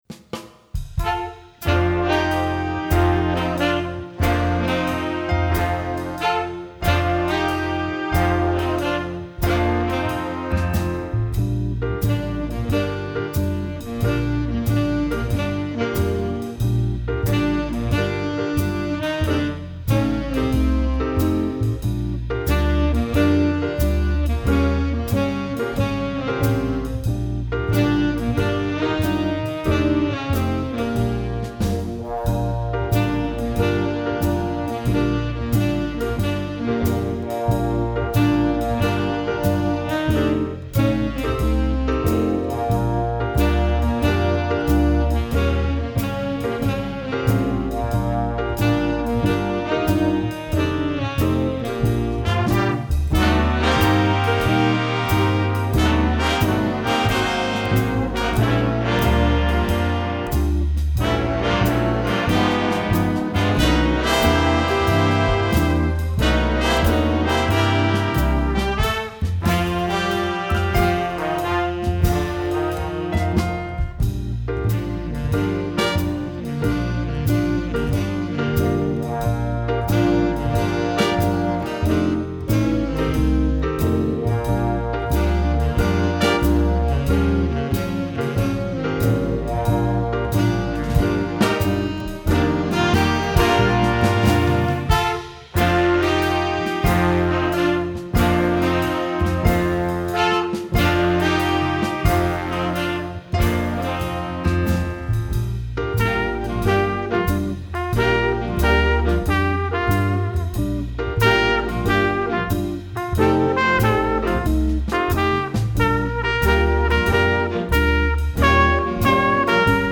Répertoire pour Jazz band - Jazz Ensemble